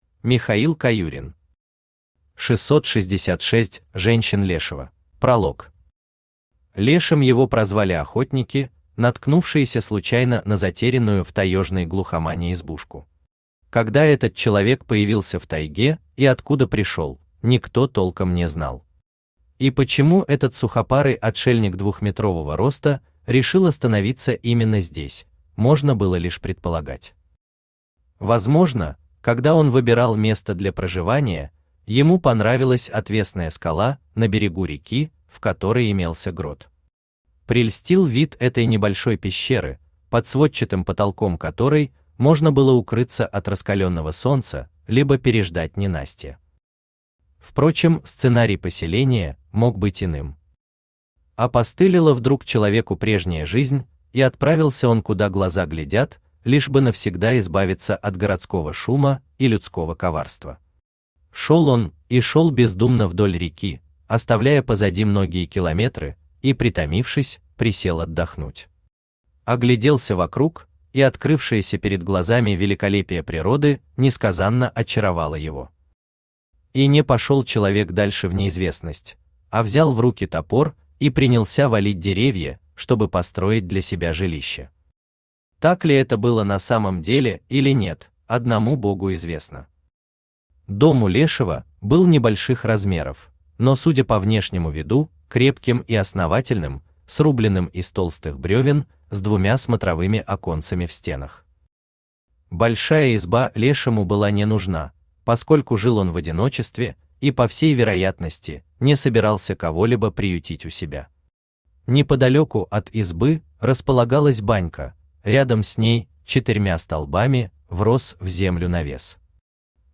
Аудиокнига 666 женщин Лешего | Библиотека аудиокниг
Aудиокнига 666 женщин Лешего Автор Михаил Александрович Каюрин Читает аудиокнигу Авточтец ЛитРес.